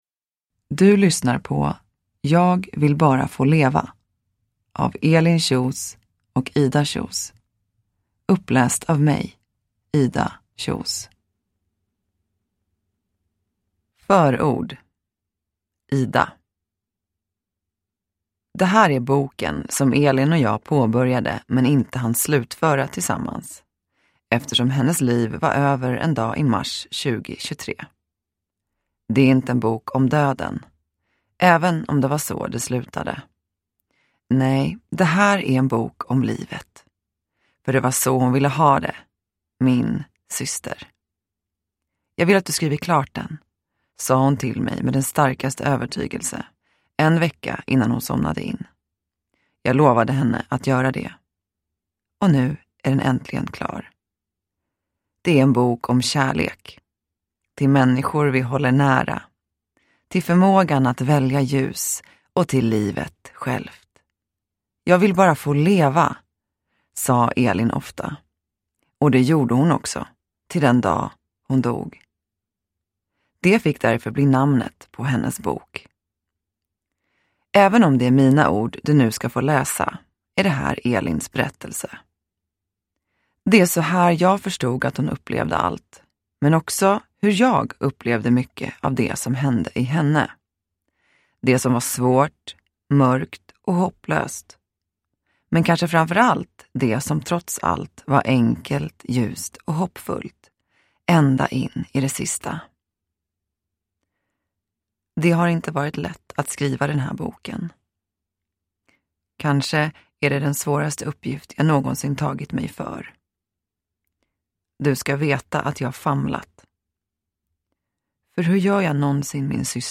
Jag vill bara få leva – Ljudbok – Laddas ner